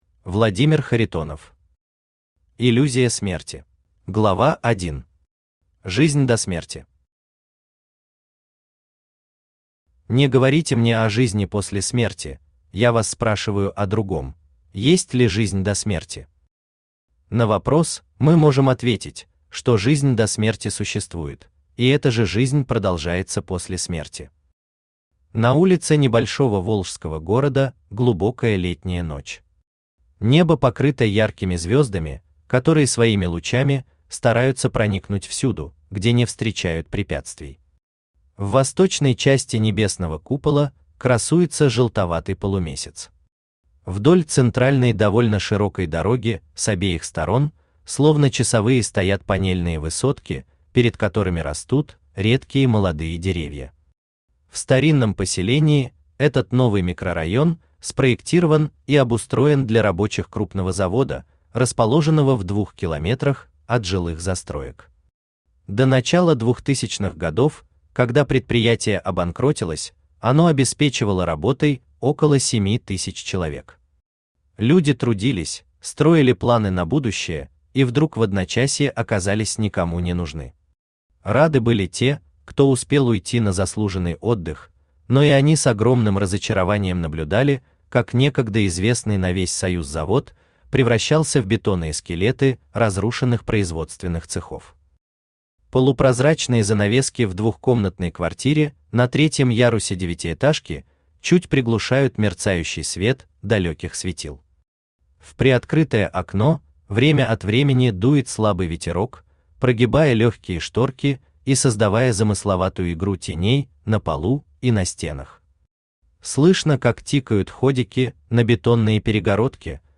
Аудиокнига Иллюзия смерти | Библиотека аудиокниг
Aудиокнига Иллюзия смерти Автор Владимир Юрьевич Харитонов Читает аудиокнигу Авточтец ЛитРес.